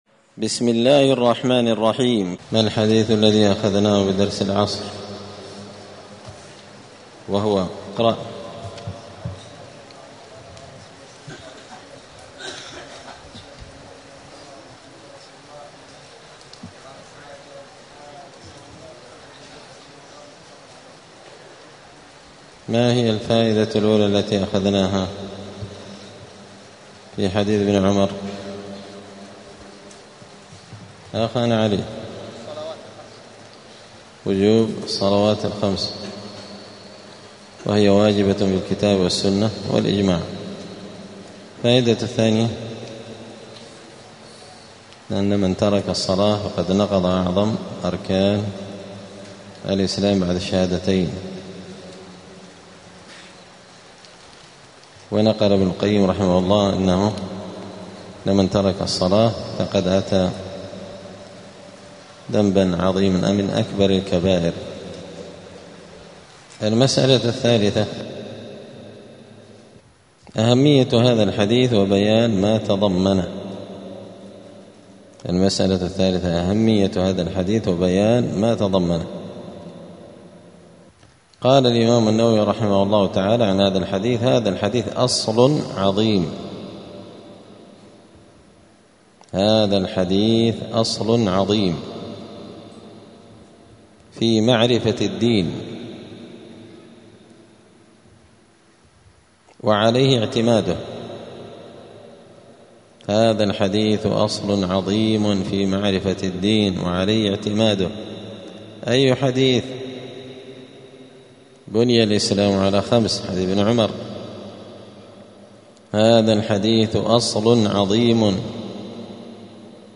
دار الحديث السلفية بمسجد الفرقان قشن المهرة اليمن
*الدرس الواحد والعشرون بعد المائة [121] {حكم من جحد أركان الإسلام}*